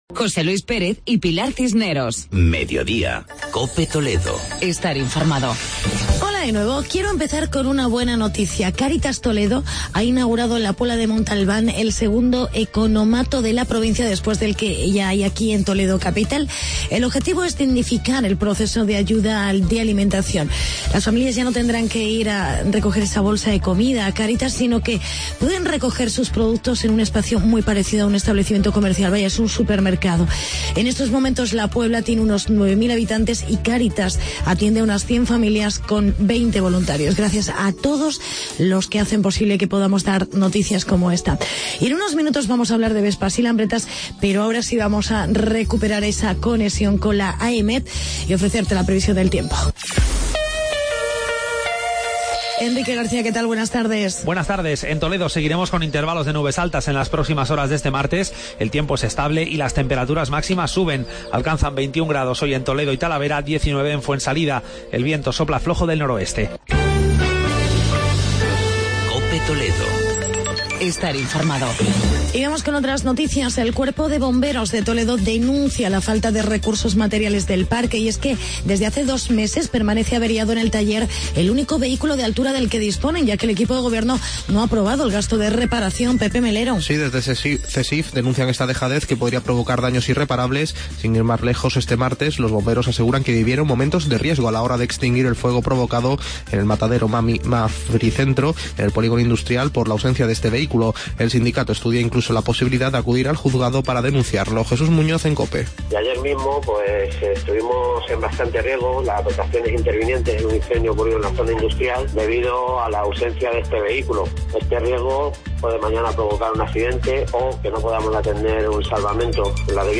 Actualidad y entrevista